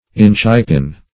inchipin - definition of inchipin - synonyms, pronunciation, spelling from Free Dictionary Search Result for " inchipin" : The Collaborative International Dictionary of English v.0.48: Inchipin \Inch"i*pin\, n. See Inchpin .